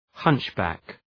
Προφορά
{‘hʌntʃ,bæk}